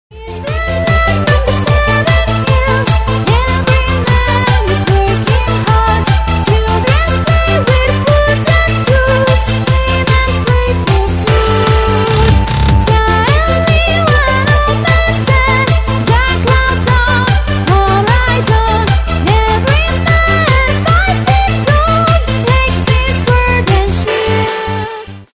分类: DJ铃声
DJ舞曲